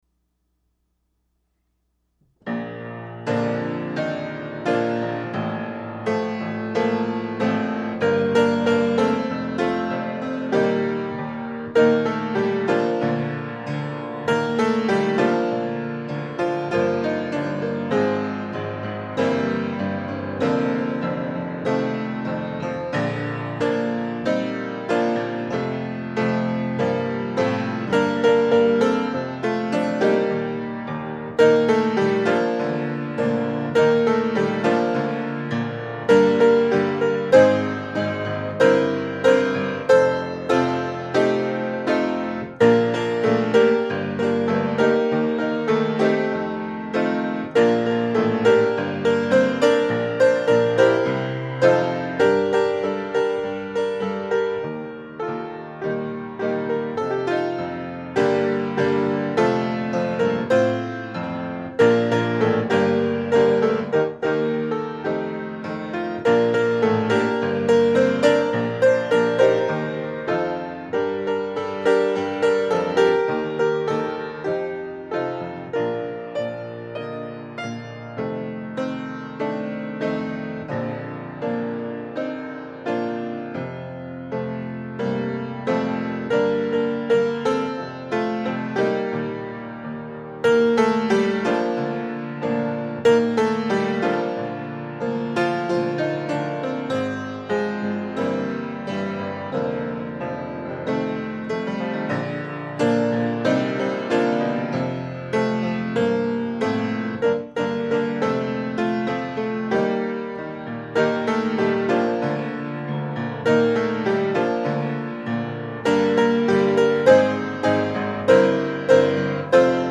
Hymns, Choruses and Songs for Worship (mp3 files)